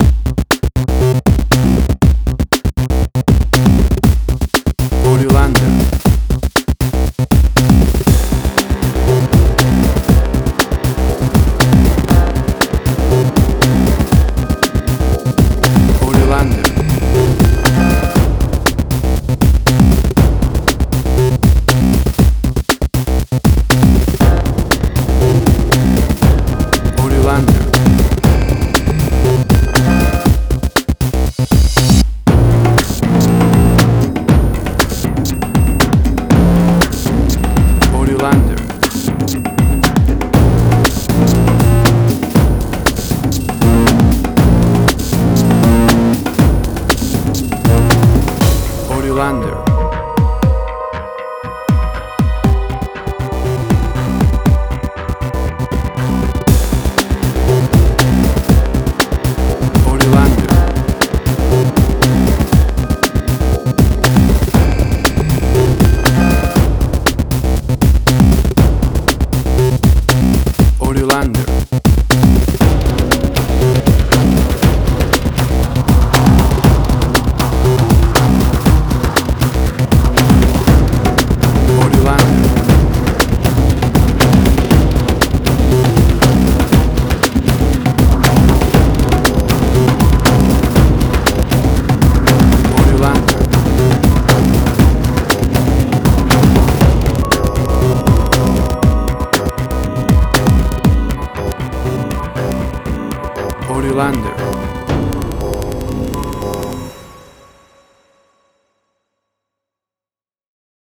Suspense, Drama, Quirky, Emotional.
Tempo (BPM): 117